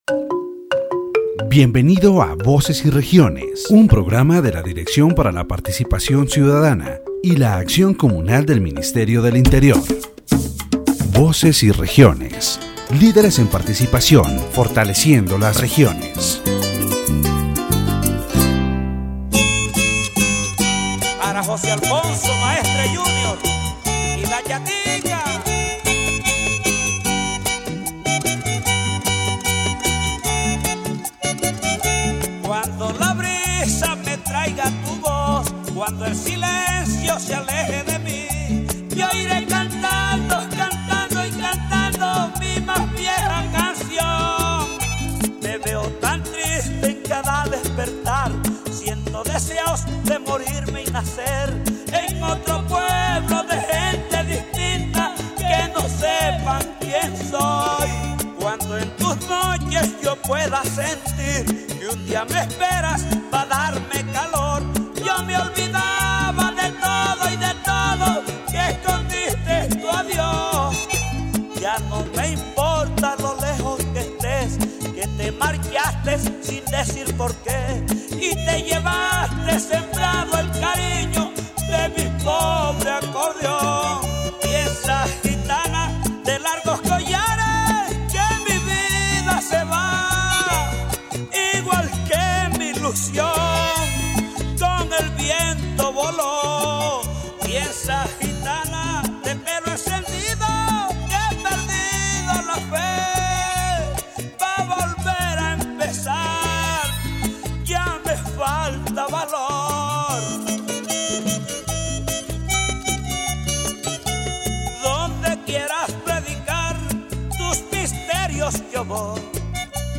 The radio program “Voces y Regiones”, produced by the Ministry of the Interior in collaboration with La U Estéreo 90.4 FM, explored the impact of women leaders in La Guajira.